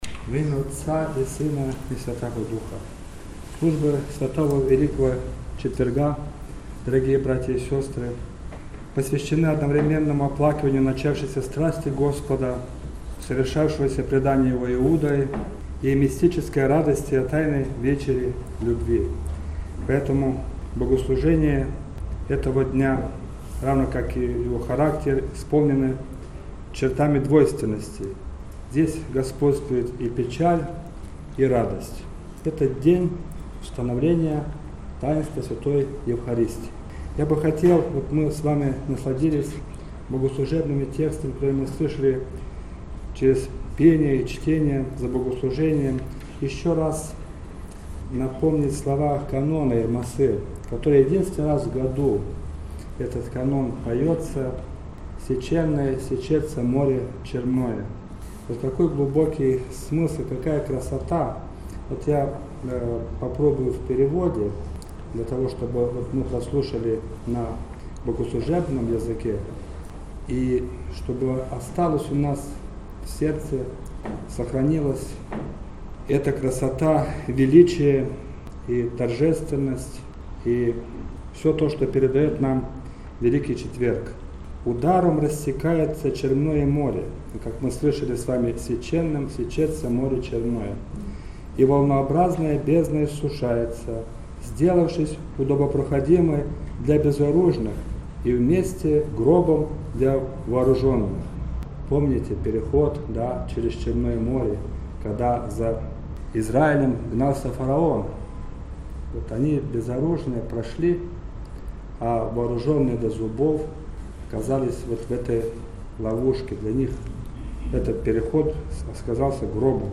Проповедь
Утреня.mp3